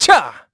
Riheet-Vox_Attack2_kr.wav